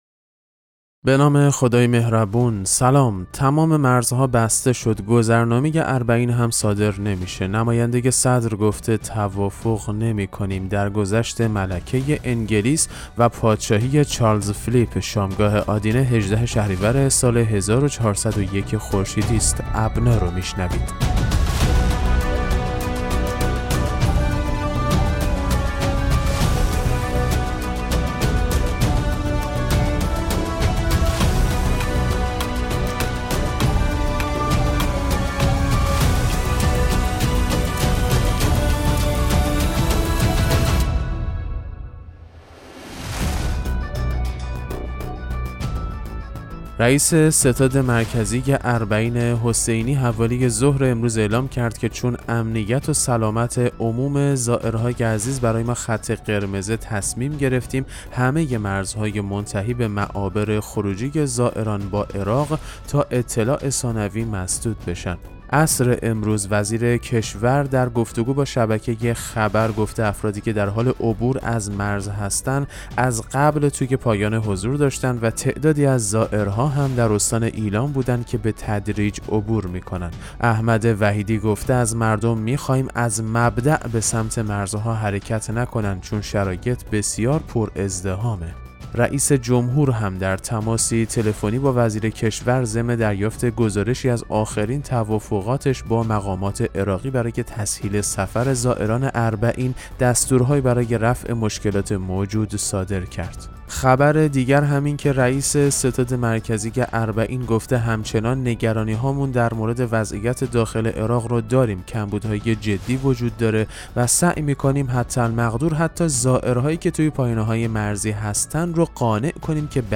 پادکست مهم‌ترین اخبار ابنا فارسی ــ 18 شهریور 1401